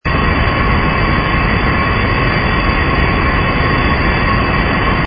engine_rh_cruise_loop.wav